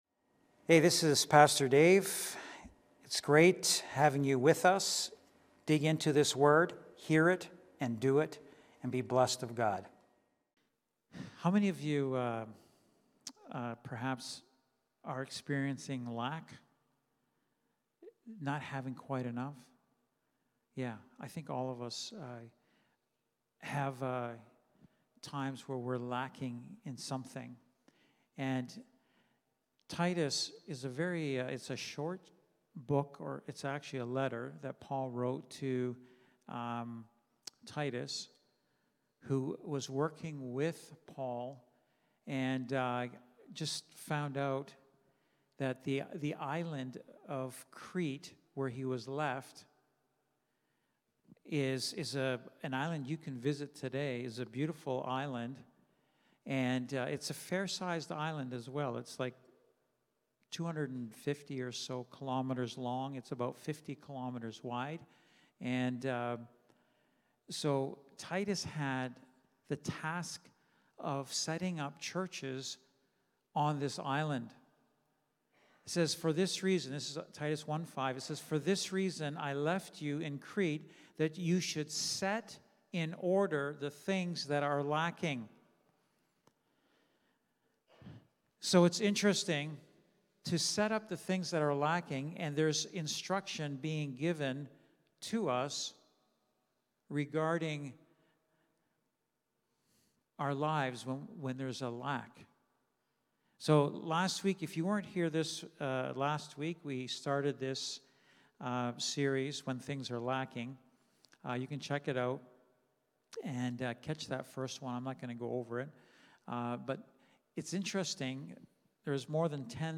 Wednesday Night Bible Study
Lighthouse Niagara Sermons